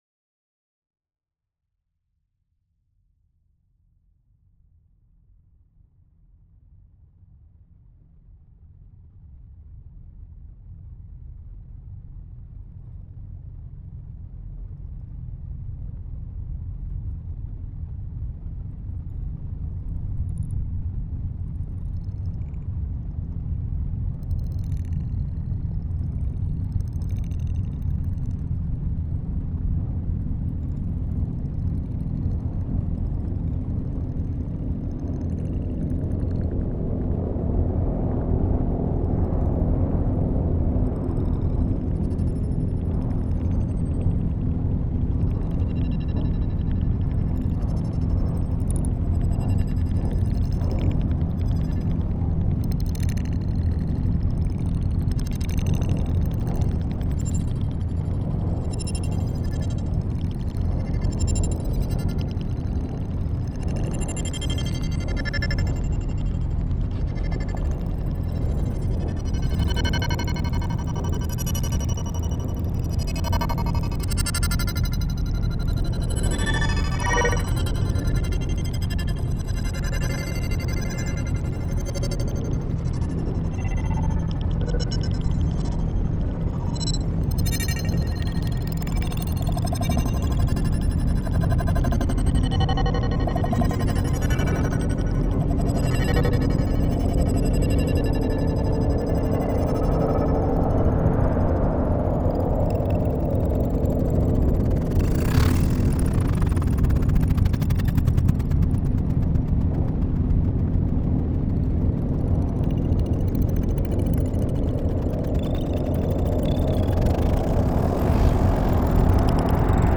made in the composer’s own studio
electroacoustic music